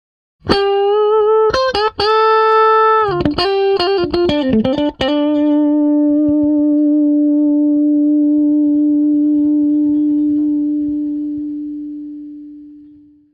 Excelente e versátil Compressor Sustainer.